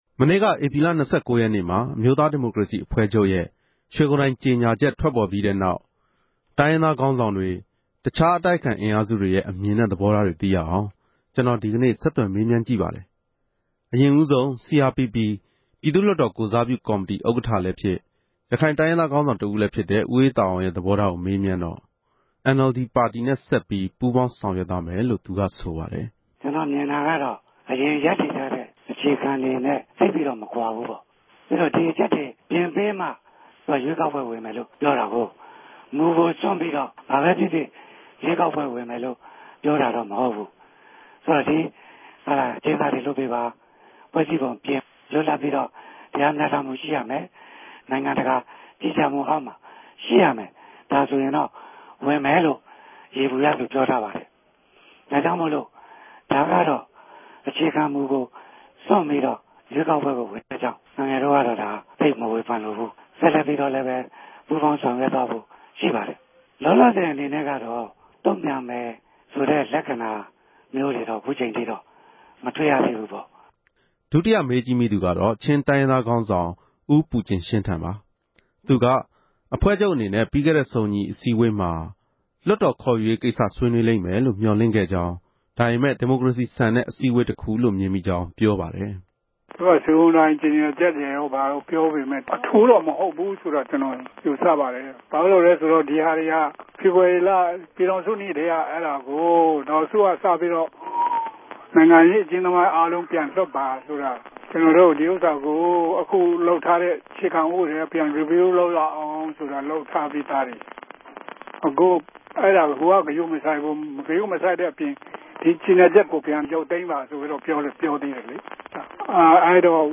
ဧ္ဘပီလ ၂၉ ရက်နေႛမြာ အမဵိြးသား ဒီမိုကရေစီအဖြဲႚခဵြပ်ရဲ့ ေ႟ဂြုံတိုင်ေုကညာခဵက် ထြက်ပေၞ္ဘပီးတဲ့နောက် တိုင်းရင်းသားခေၝင်းဆောင်တြေ၊ တူခားအတိုက်အခံ အင်အားစုတေရြဲ့ အူမင်နဲႛ သဘောထားတေကြို RFAက ဆက်သြယ်မေးူမန်းခဲ့ပၝတယ်။